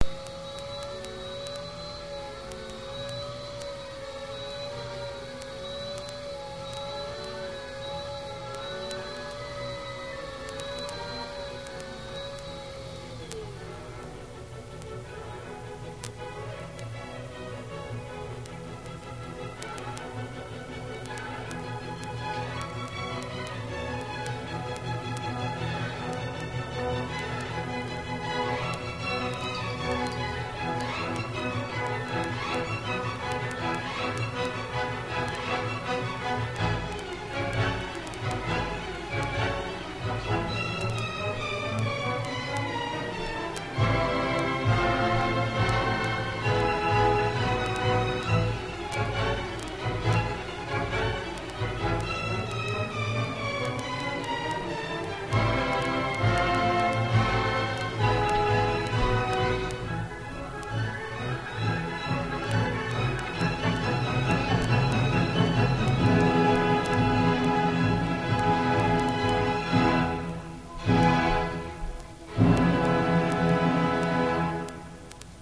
for ecstatic acceleration towards an inevitable climax